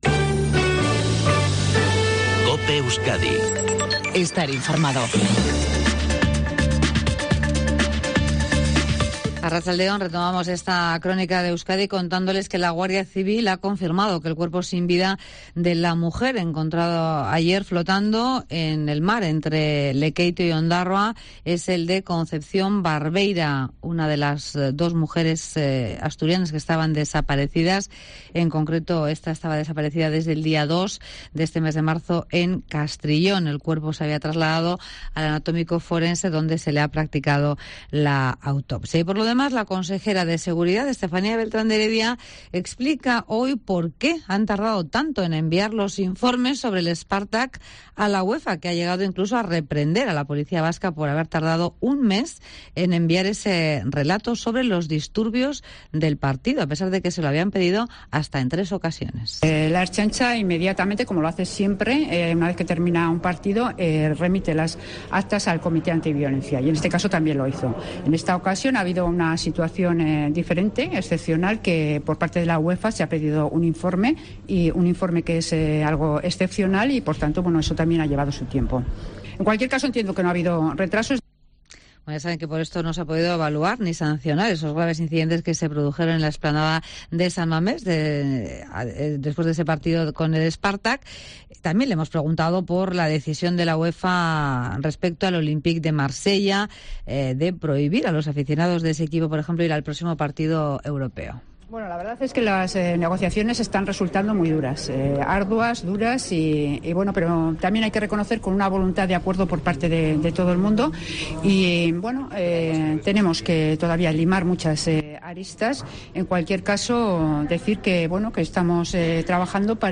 INFORMATIVO MEDIODÍA EUSKADI 14:48h